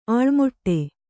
♪ āḷmoṭṭe